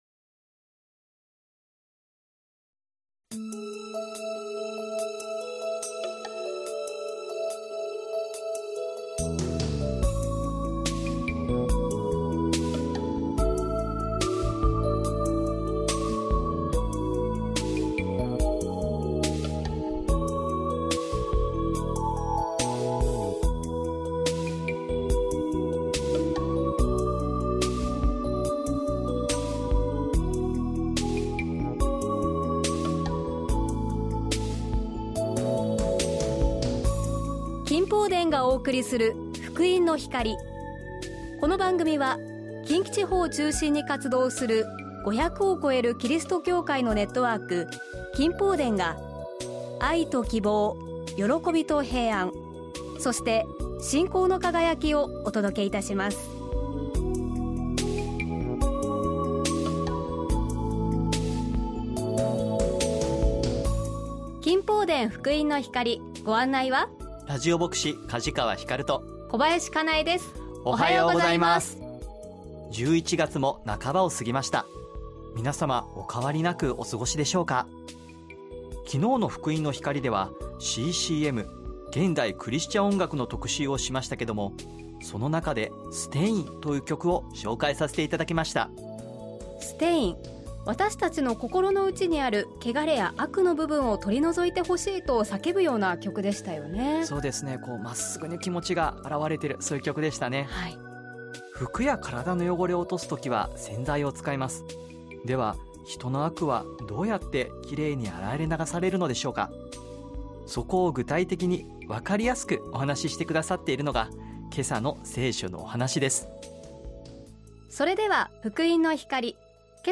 御言葉とお話「御子イエスの血によって罪がきよめられる」
信仰体験談